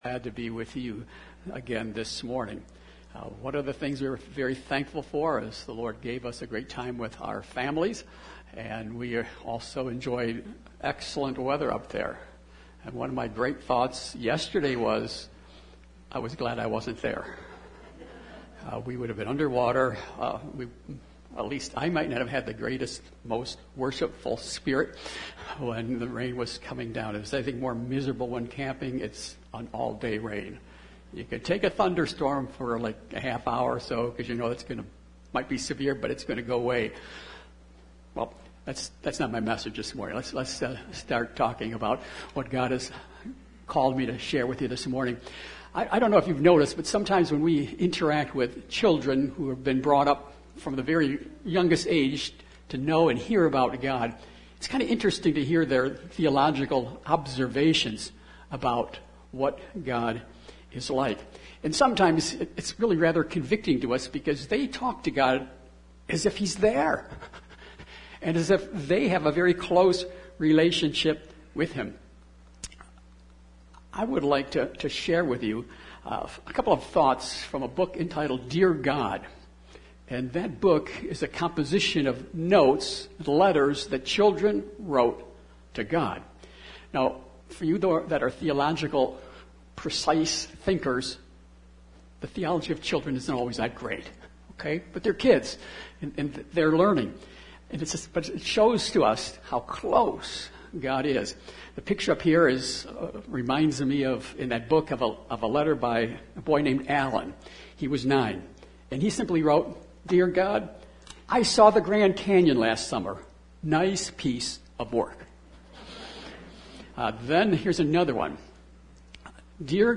Aug 14, 2022 Portraits of the People of God MP3 SUBSCRIBE on iTunes(Podcast) Notes Sermons in this Series 1 Peter 2:4-12 Thank You, Peter!